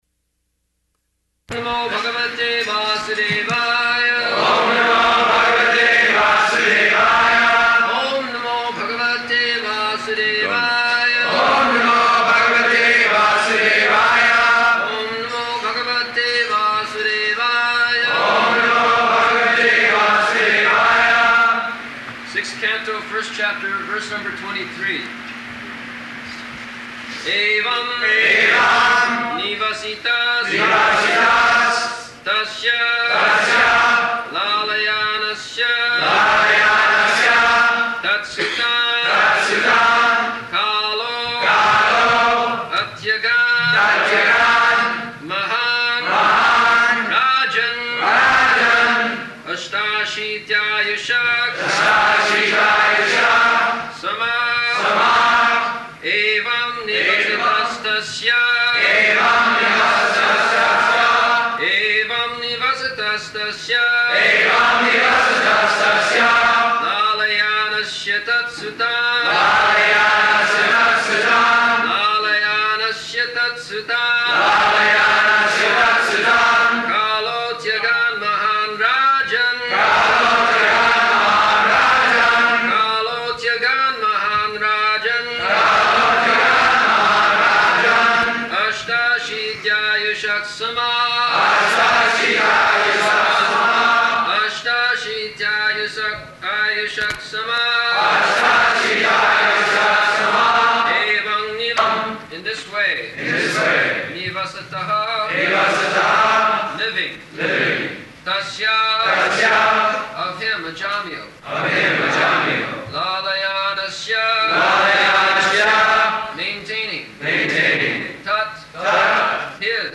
July 7th 1975 Location: Chicago Audio file
[devotees repeat] Sixth Canto, First Chapter, verse number 23.